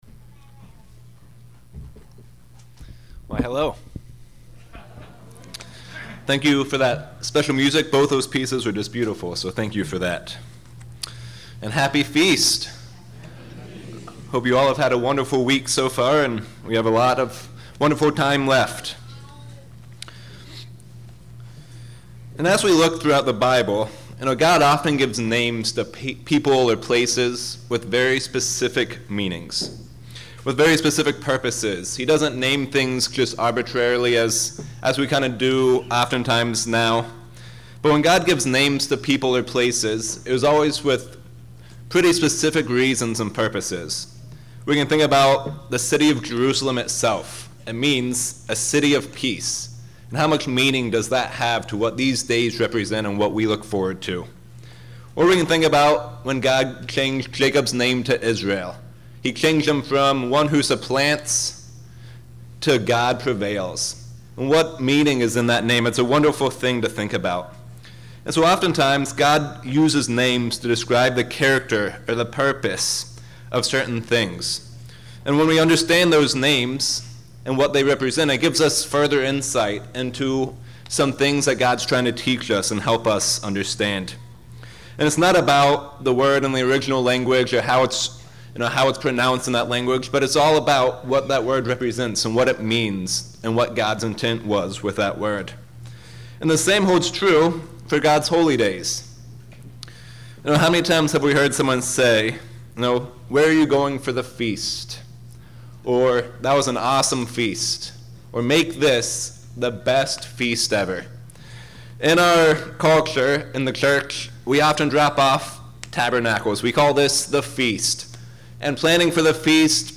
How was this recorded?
This sermon was given at the Lake Texoma, Texas 2017 Feast site.